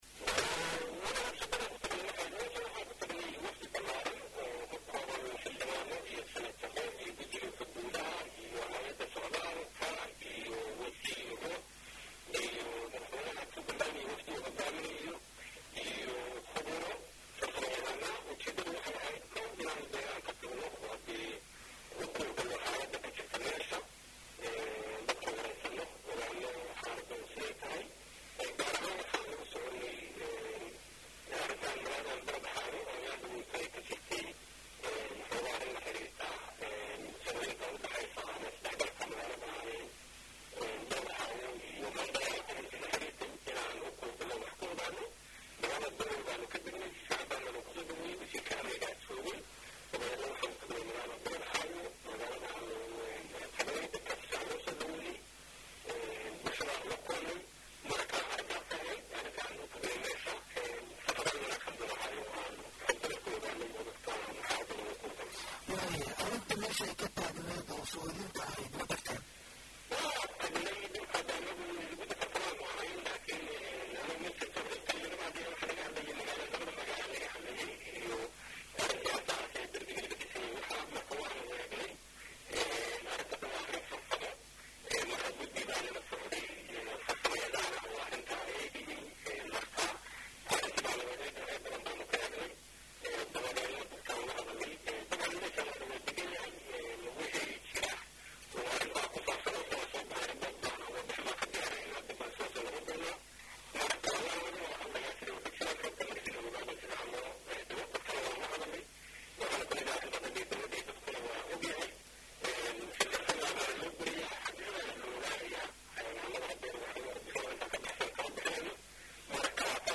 Wasiirka Wasaaradda Arimaha Gudaha Federaalka iyo dubi heshiisiinta Xukumadda Federaalka Soomaaliya Mudane Cabdi Faarxa Saciid Juxa oo la hadlay Radio
Halkaan hoose ka dhageyso Codka Wasiirka.